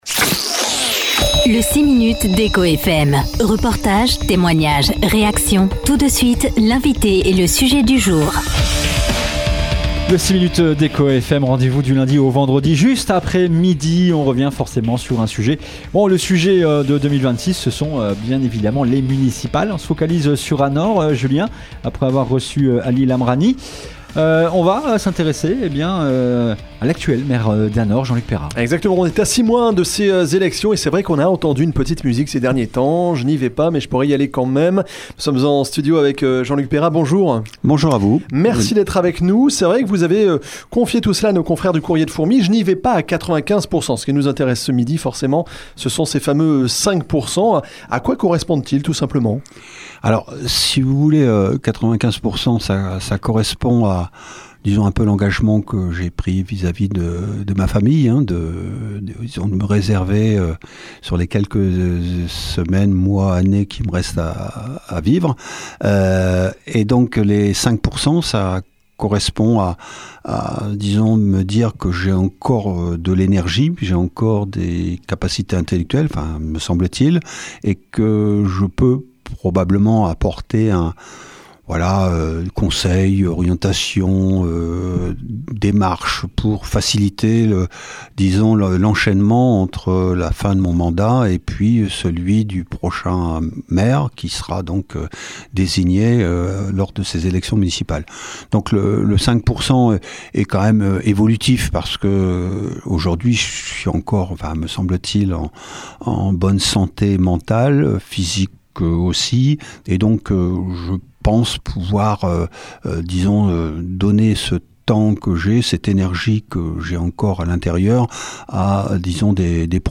Ce jeudi 25 septembre, le maire d’Anor, Jean-Luc Pérat, était l’invité d’Echo FM.